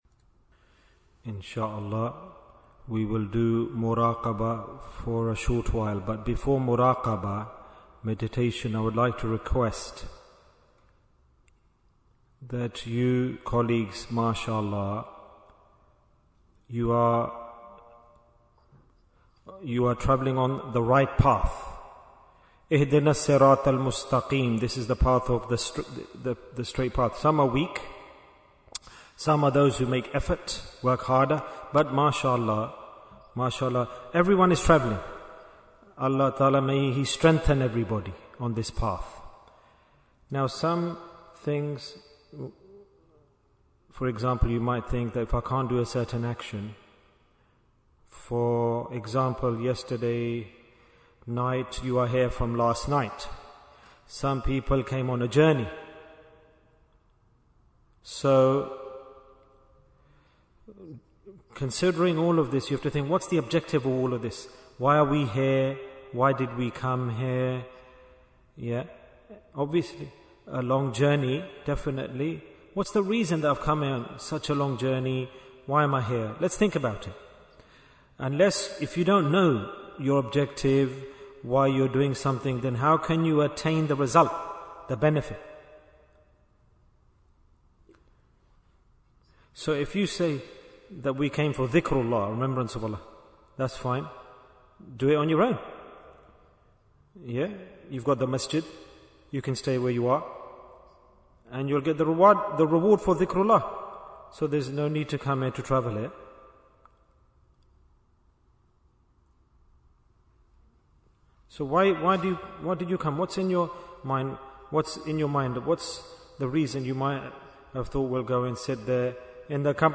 Bayan after Zuhr in Manchester Bayan, 38 minutes25th August, 2025